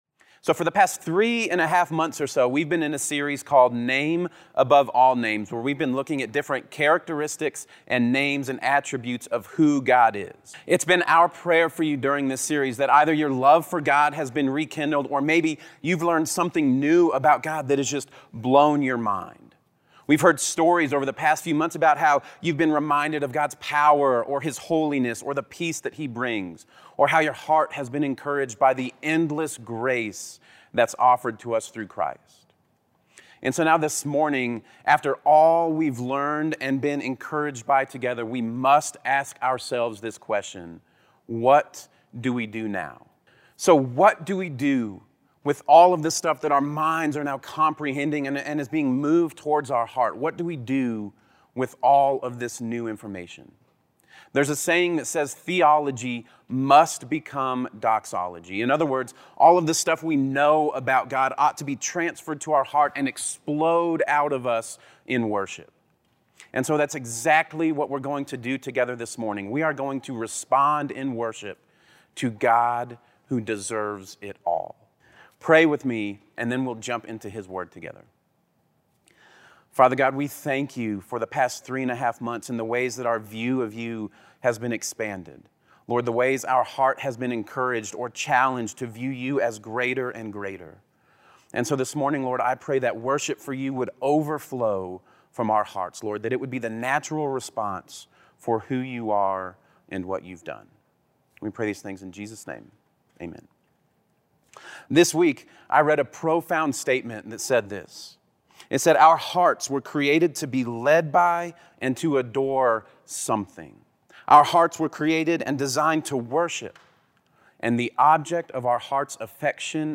Sermon MP3 Download G2Q Word Doc Download G2Q PDF